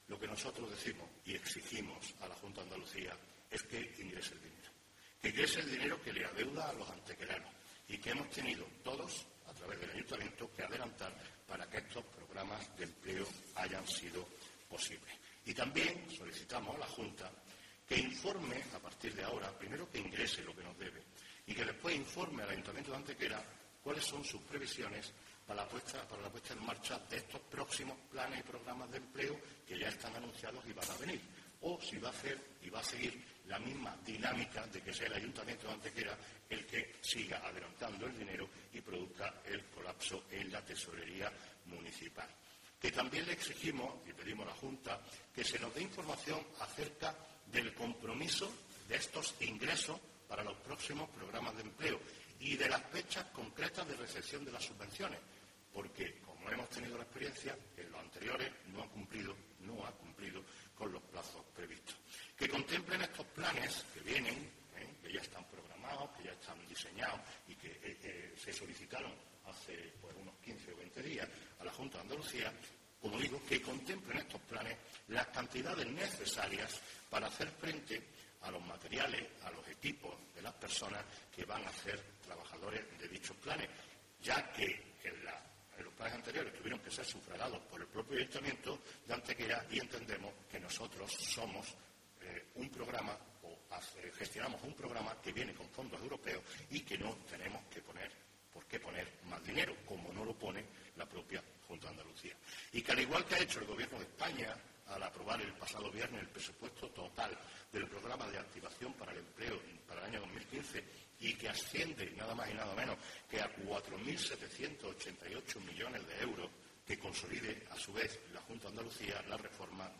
Cortes de voz
Audio Manolo Barón   1433.57 kb  Formato:  mp3